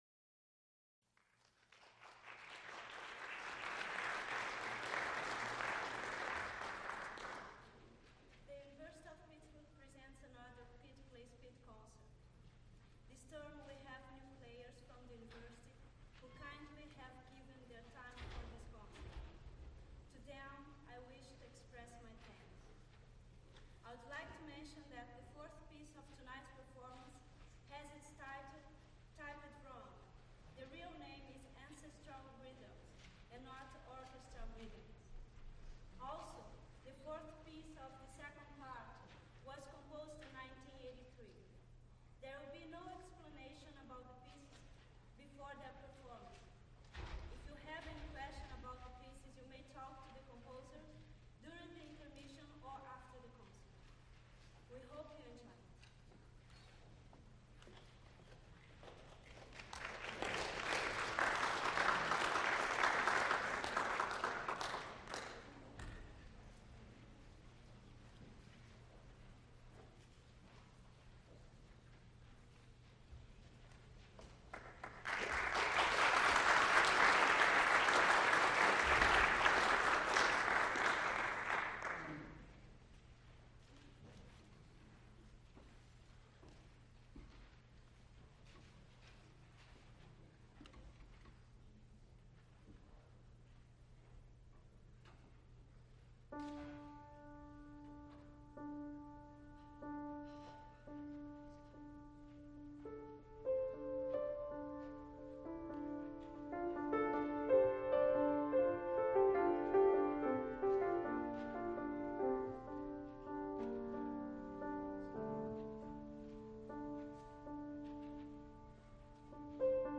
First half of concert only.
Various performers, Recorded live December 1, 1983
Extent 1 audiotape reel : analog, half track, stereo, 7 1/2 ips ; 12 in.
musical performances
Piano music
Songs (Medium voice) with piano
Songs (High voice) with instrumental ensemble Violin and piano music Trios (Piano, flute, cello)